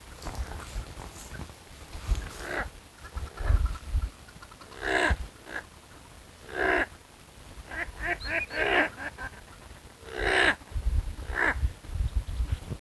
Crested Duck (Lophonetta specularioides)
Detailed location: Laguna runtuyok
Condition: Wild
Certainty: Photographed, Recorded vocal